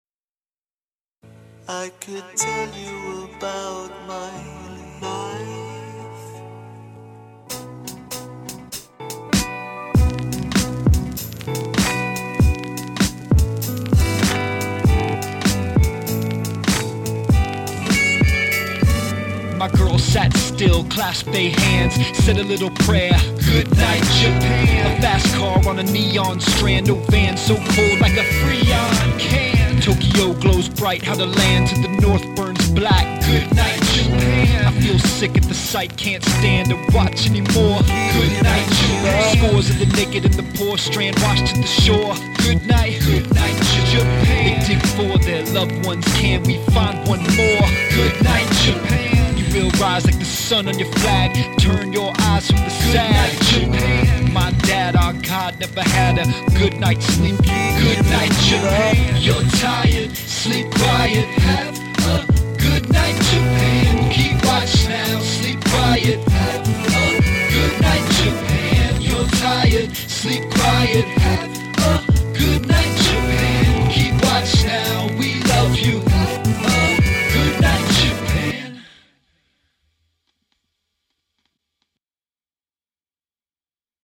A Lullaby